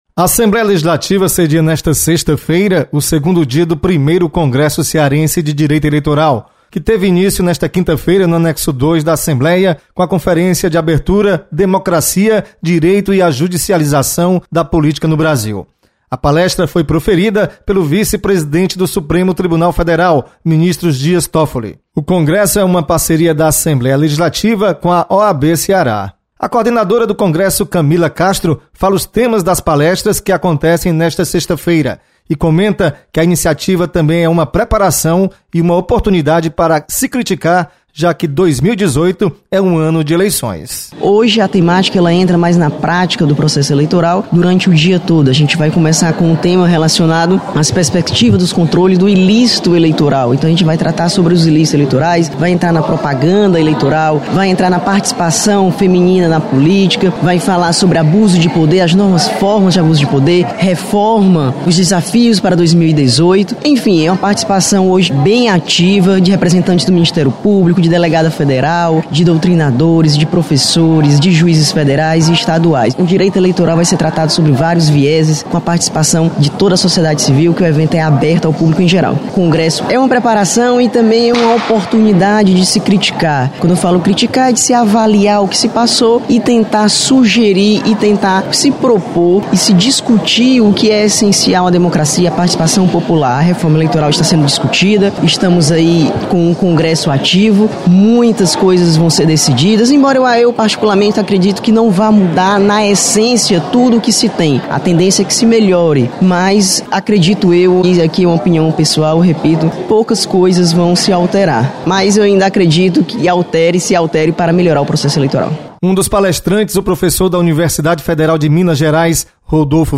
Prossegue na Assembleia Primeiro Congresso Cearense de Direito Eleitoral. Repórter